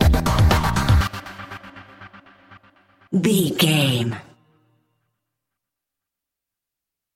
Epic / Action
Aeolian/Minor
drum machine
synthesiser
electric piano
Eurodance